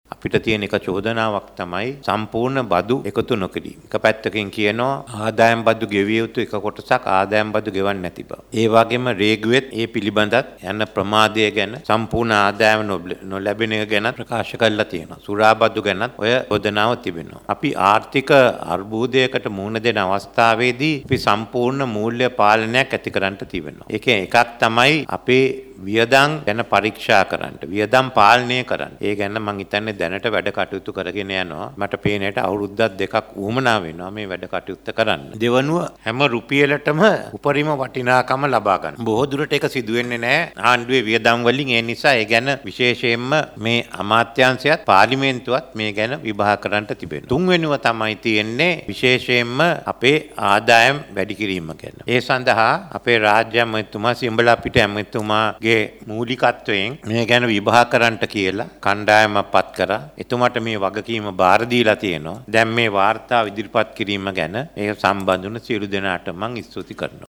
මේ එහිදී වැඩිදුරටත් අදහස් දැක්වූ ජනාධිපති රනිල් වික්‍රමසිංහ මහතා.